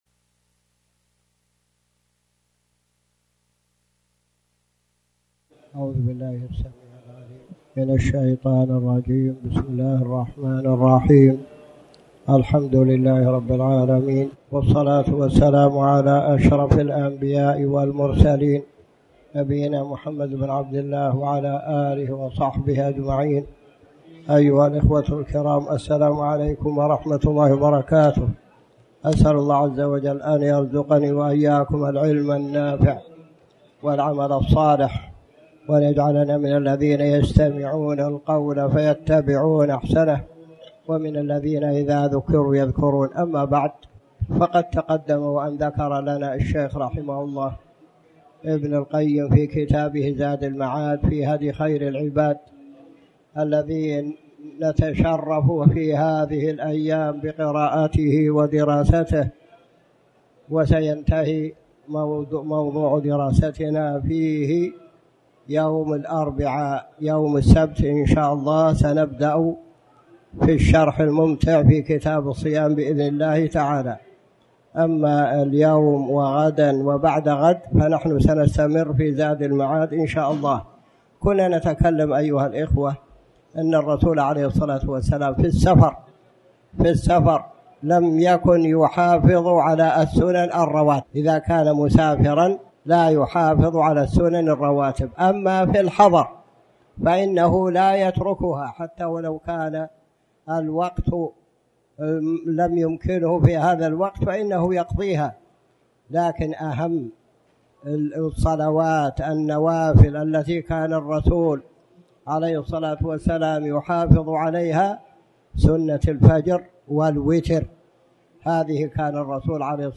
تاريخ النشر ٣٠ رجب ١٤٣٩ هـ المكان: المسجد الحرام الشيخ